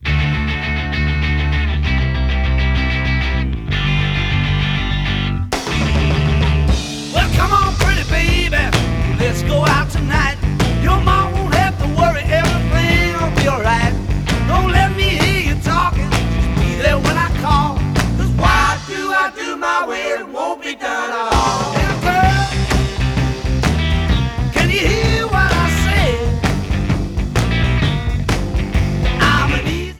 Stereo